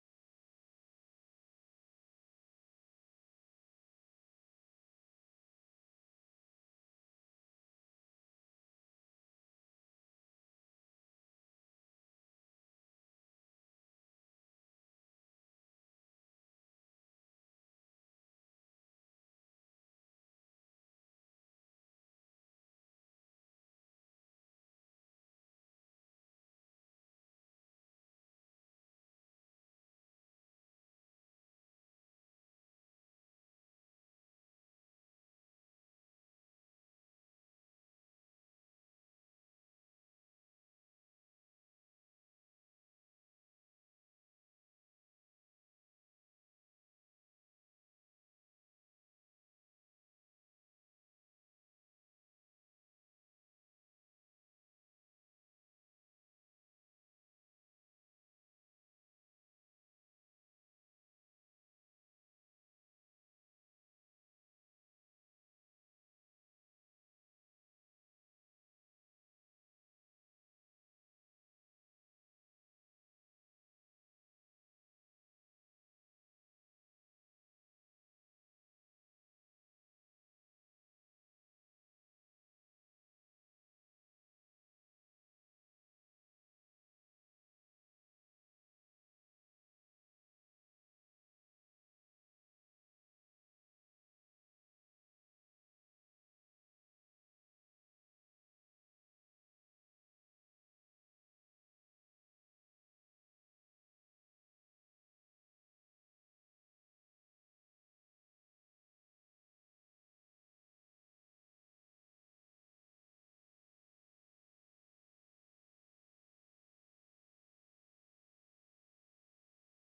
presidio piazza indipendenza.ogg